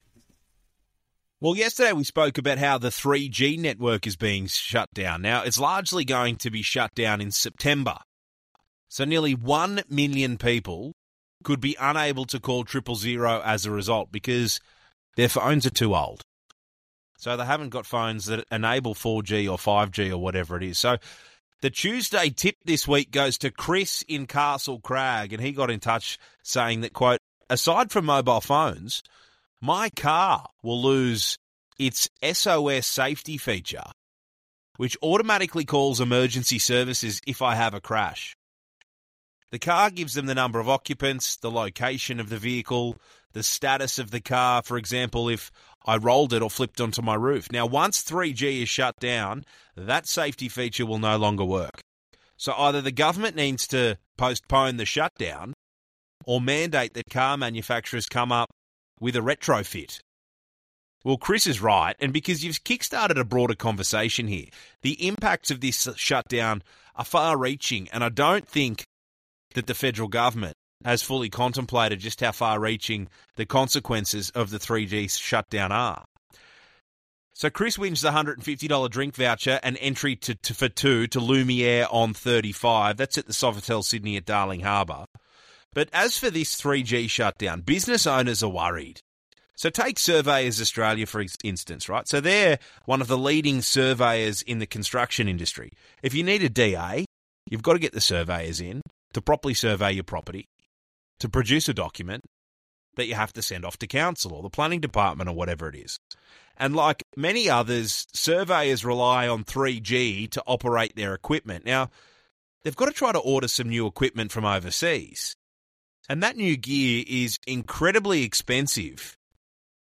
as she calls in to 2GB Sydney Radio station to speak with Journalist and Host Chris O'Keefe about the impact of the 3G shutoff on the Surveying Industry and its businesses.